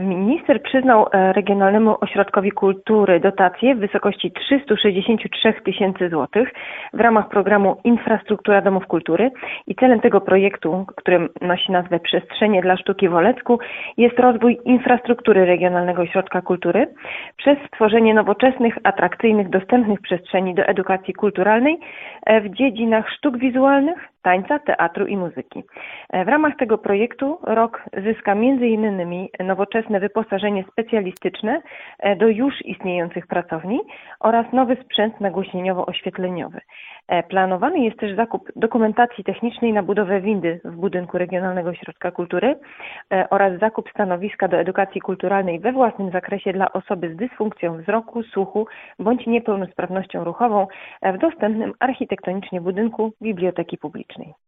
O szczegółach mówiła w czwartek (20.03.25) w Radiu 5 Sylwia Wieloch, zastępca burmistrza Olecka.